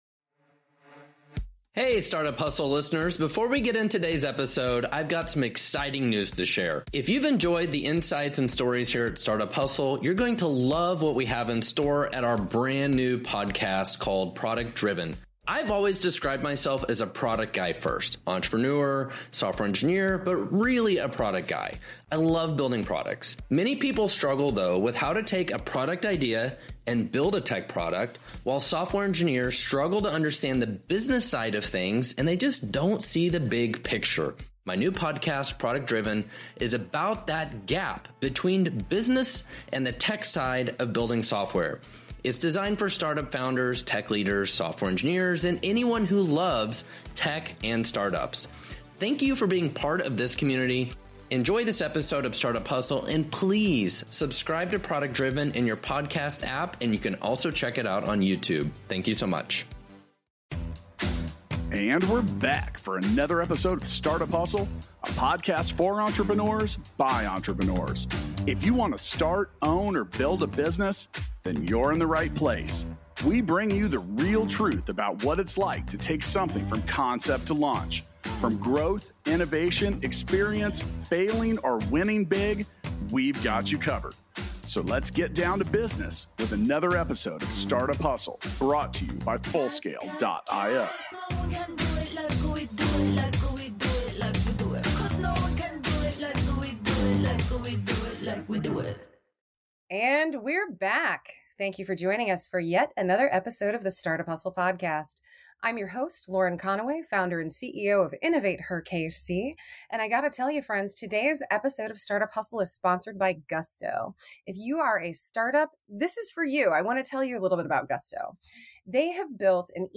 a dynamic conversation